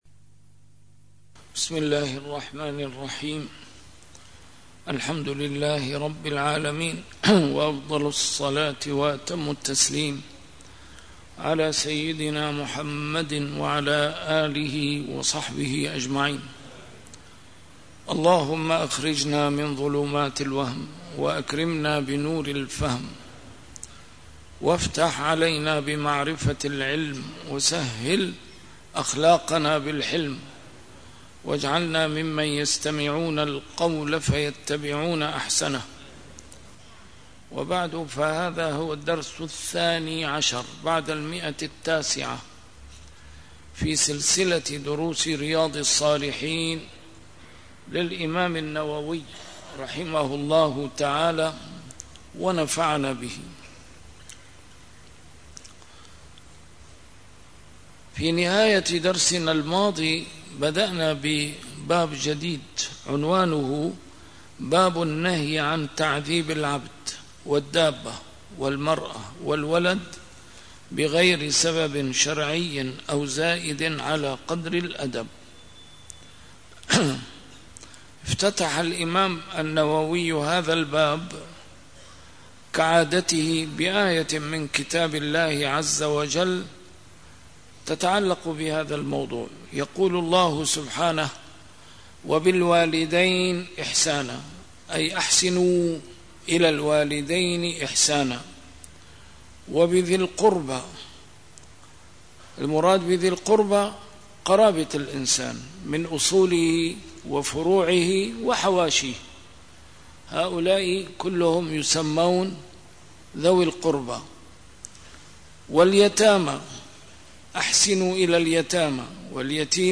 A MARTYR SCHOLAR: IMAM MUHAMMAD SAEED RAMADAN AL-BOUTI - الدروس العلمية - شرح كتاب رياض الصالحين - 912- شرح رياض الصالحين: النهي عن تعذيب العبد والدابة والمرأة والولد